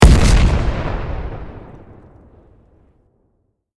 medium-explosion-1.ogg